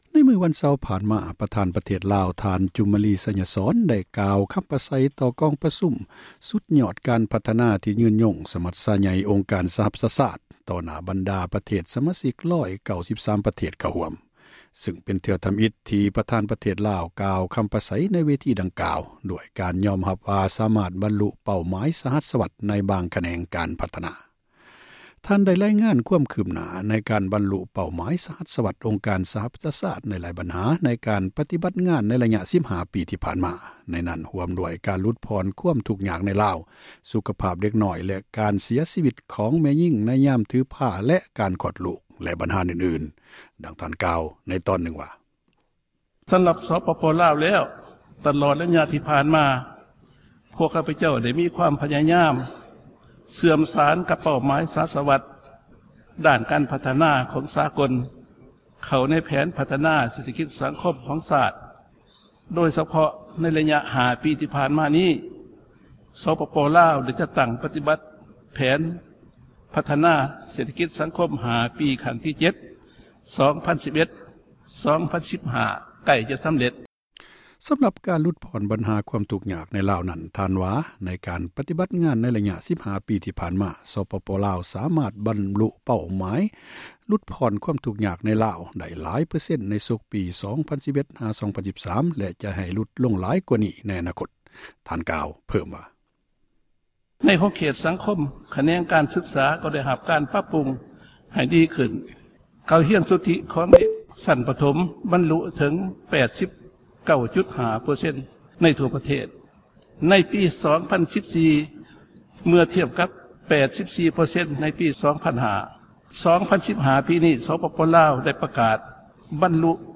ປະທານປະເທດລາວກ່າວຢູ່ ສປຊ
ທ່ານ ຈູມມະລີ ໄຊຍະສອນ ຂຶ້ນກ່າວ ໃນ ກອງປະຊຸມ ສຸດຍອດ ການພັທນາ ທີ່ ຍືນຍົງ ສະຫະ ປະຊາຊາດ ກ່ຽວກັບ ຄວາມຄືບຫນ້າ ຂອງ ສປປ ລາວ.